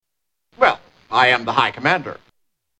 Category: Television   Right: Personal
Tags: 3rd Rock from the Sun TV sitcom Dick Solomon John Lithgow Dick Solomon clips